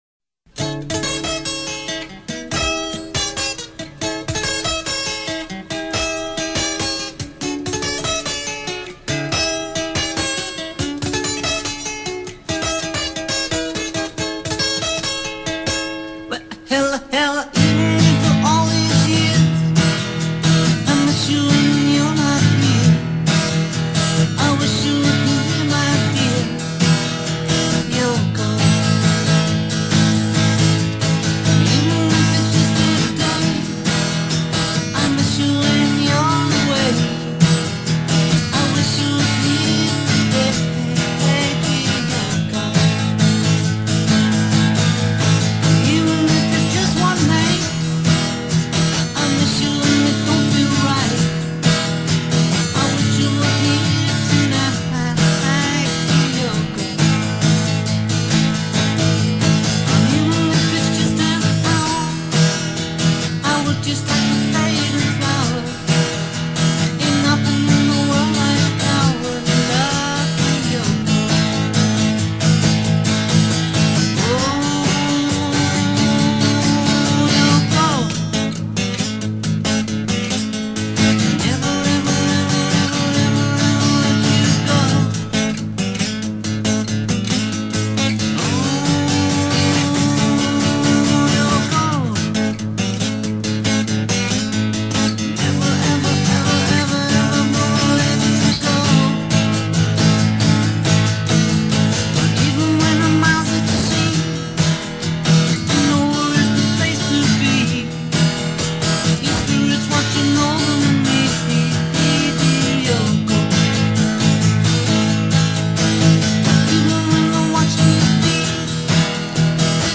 音樂類型：西洋音樂